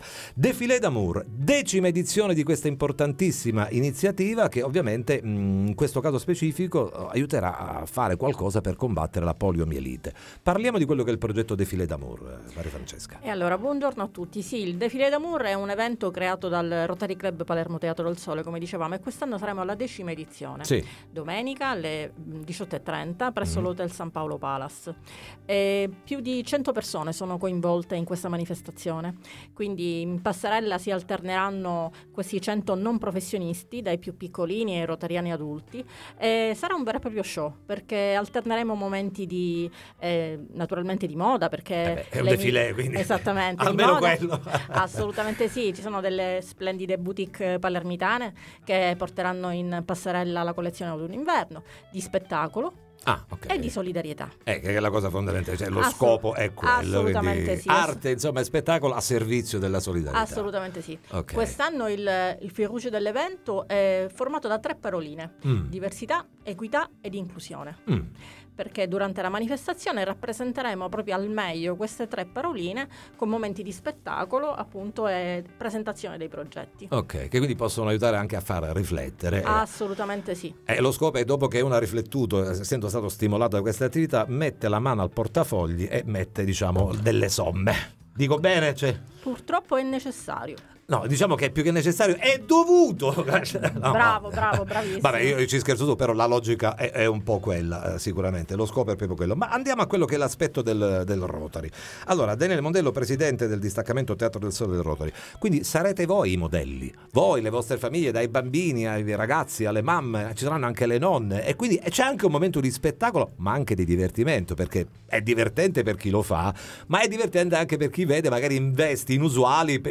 Il “Défilé d’Amour” del Rotary: a Palermo sfilata di moda per combattere la poliomielite, ne parliamo con gli organizzatori